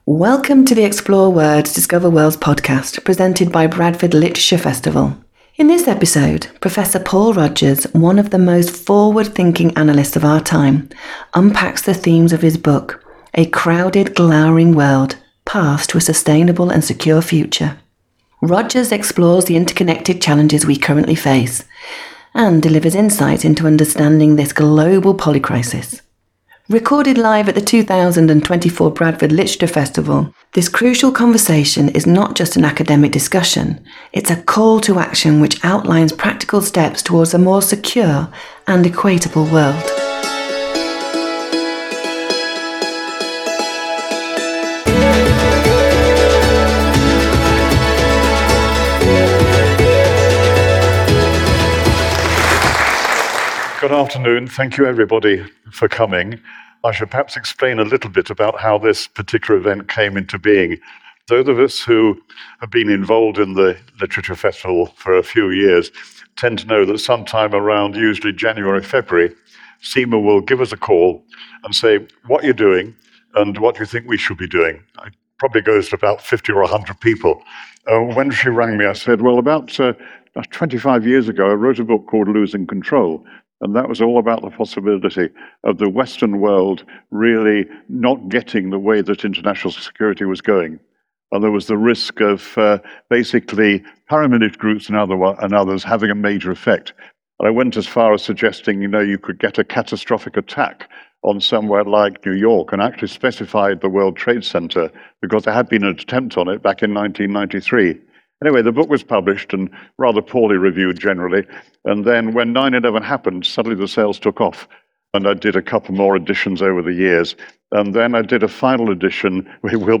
Join us for a crucial conversation with Professor Paul Rogers, one of the most forward-thinking analysts of our time, as he discusses his latest book, A Crowded, Glowering World: Paths to a Sustainable and Secure Future. This talk will explore the interconnected challenges we currently face and deliver crucial insights into understanding this global ‘polycrisis’.